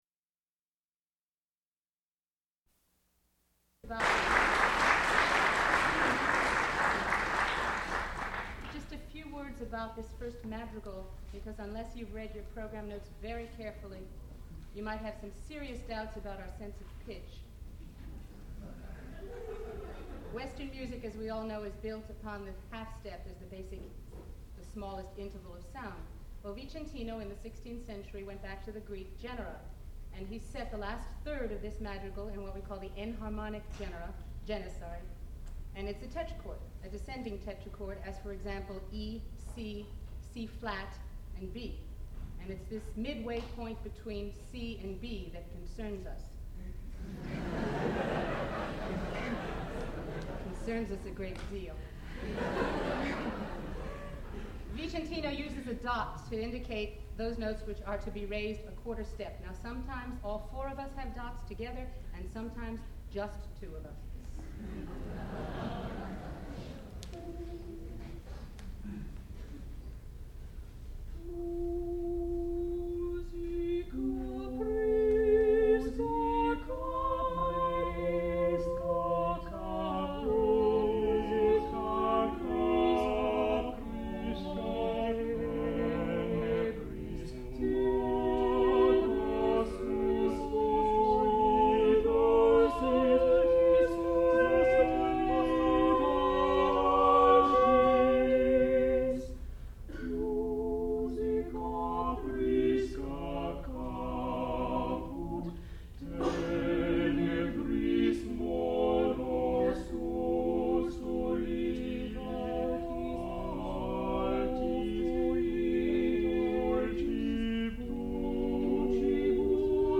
sound recording-musical
classical music
baritone
mezzo-soprano
tenor